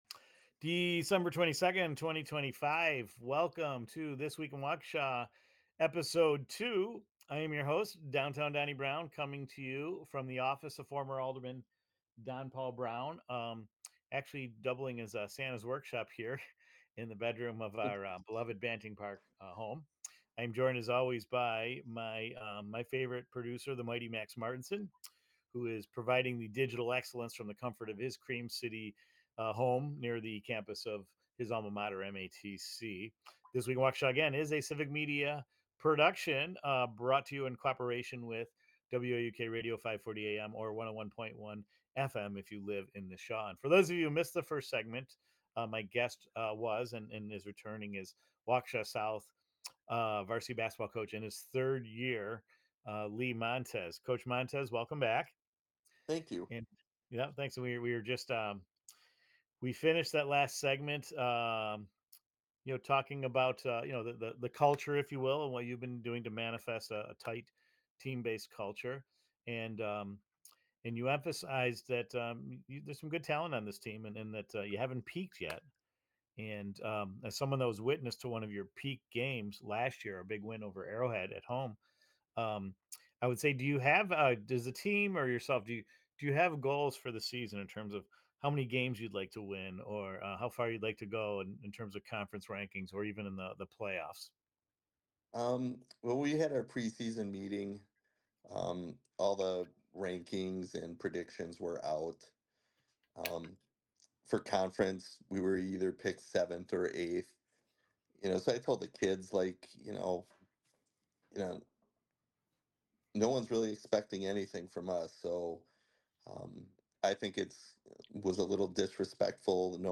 Broadcasting from the office of former Alderman Don Browne in the Banting Park neighborhood